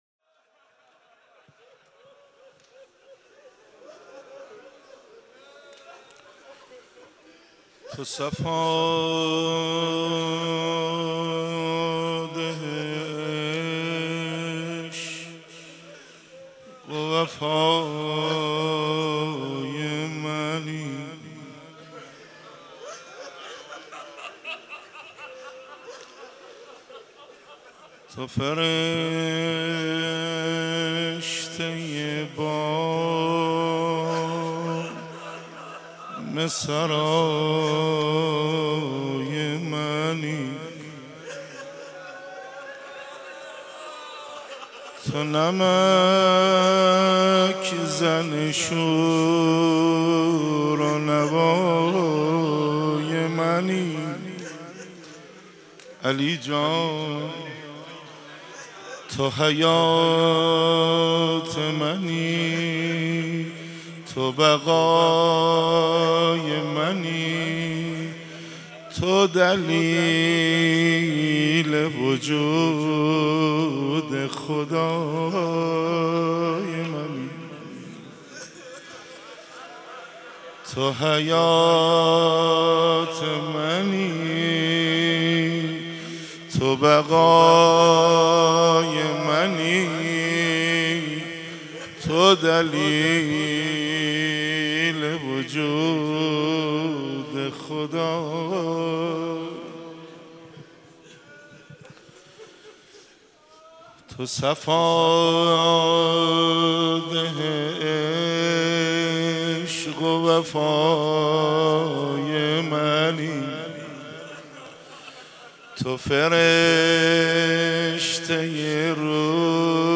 روضه
روضه شب ٢٣ رمضان.mp3